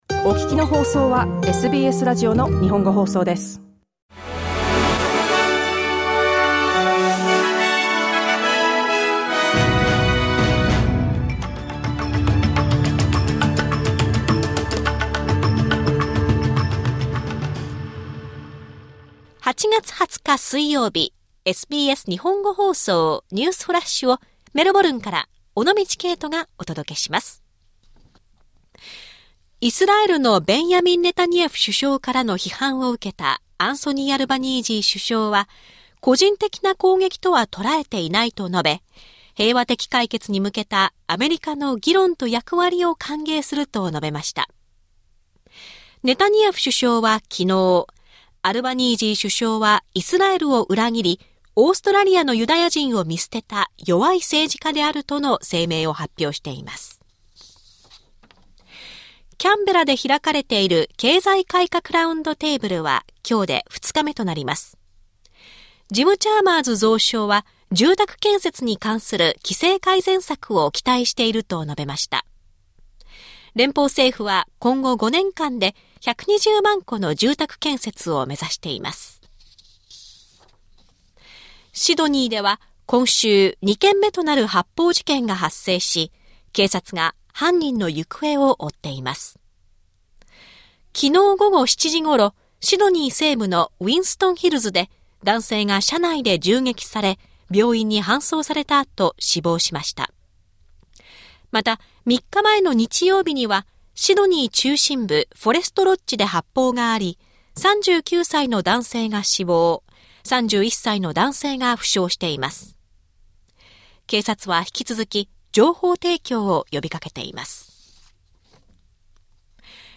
SBS日本語放送ニュースフラッシュ 8月20日 水曜日